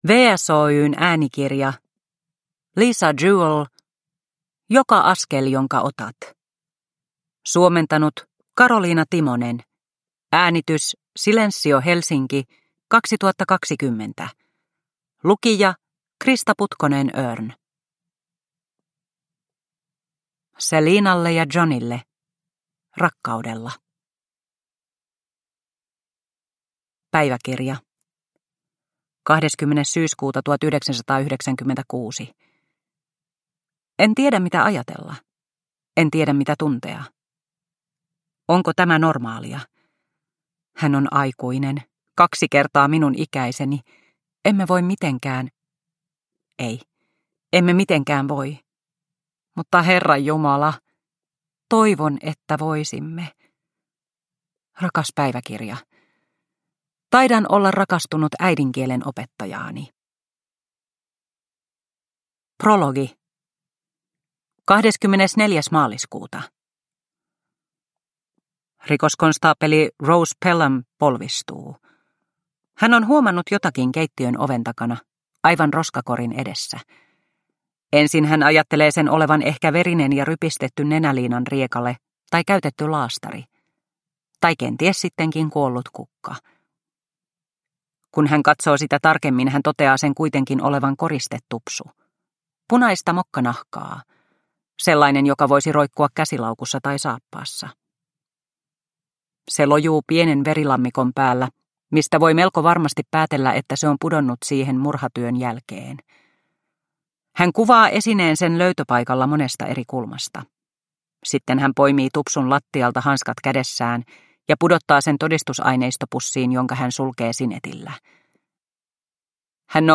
Joka askel jonka otat – Ljudbok – Laddas ner